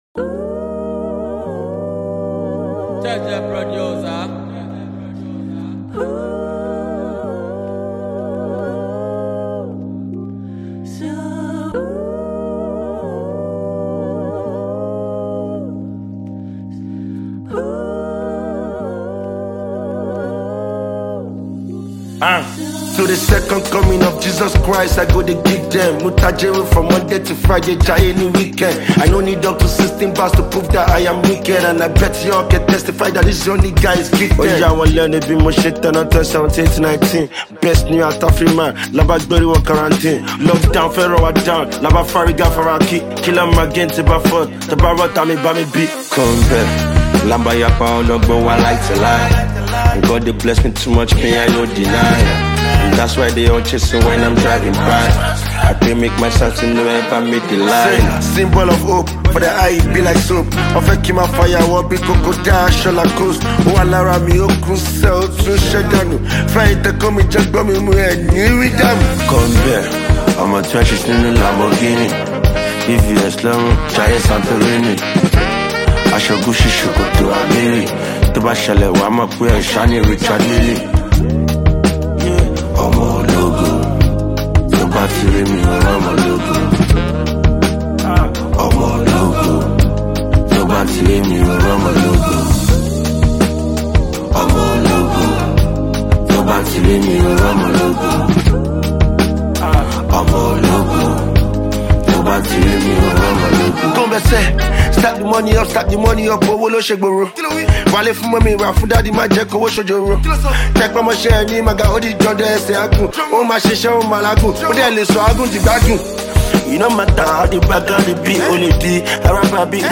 is a Nigerian rapper and singer.